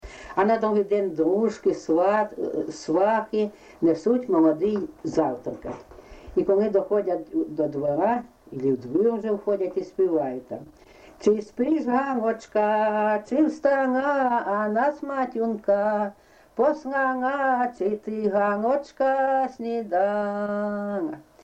ЖанрВесільні
Місце записус. Курахівка, Покровський район, Донецька обл., Україна, Слобожанщина